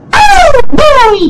OH BOY mokey sound button getting viral on social media and the internet Here is the free Sound effect for OH BOY mokey that you can download and make